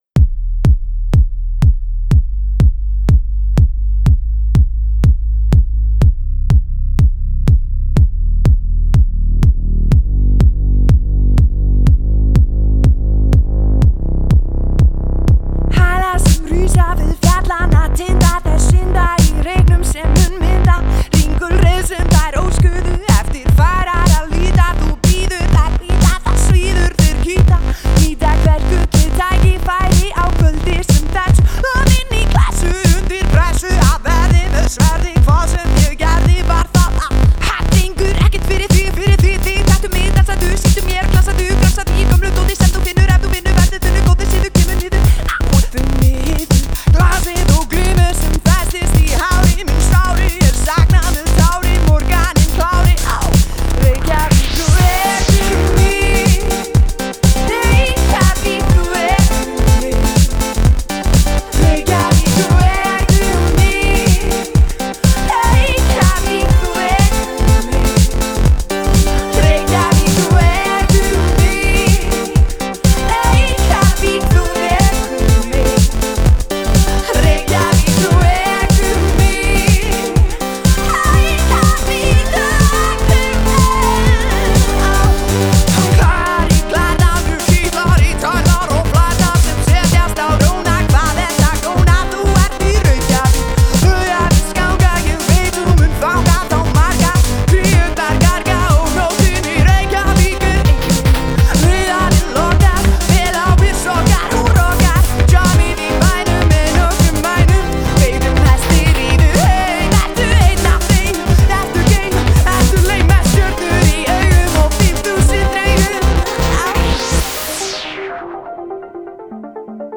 albeit quickfire over a backdrop of syrupy house.
synth band